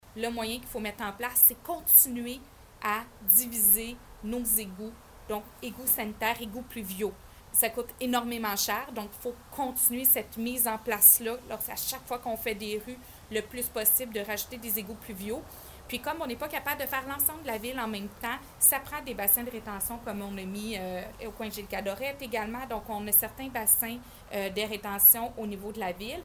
Une autre priorité est la gestion des eaux pluviales pour prévenir les inondations en divisant le système d’égouts combiné en deux réseaux distincts comme l’explique Mme Bourdon :